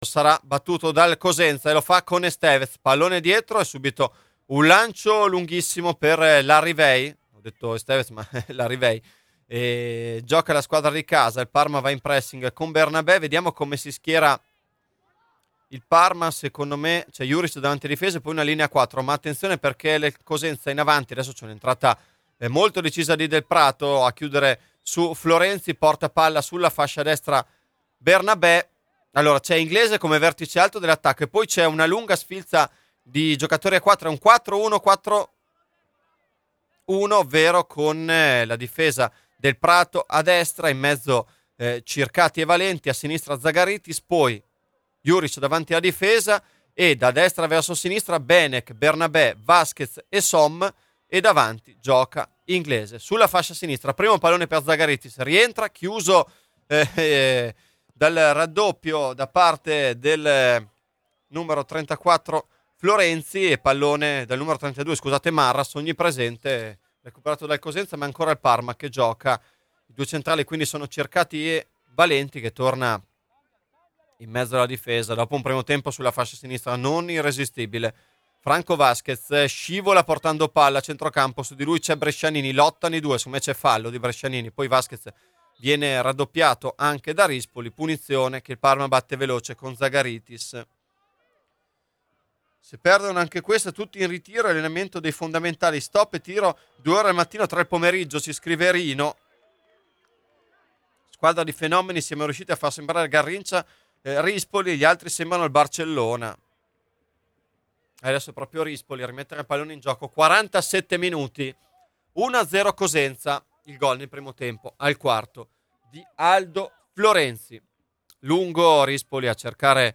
Radiocronache Parma Calcio Parma - Cosenza 28 gennaio 2023 - 2° tempo Jan 28 2023 | 00:51:29 1x 00:00 / 00:51:29 Subscribe Share RSS Feed Share Link Embed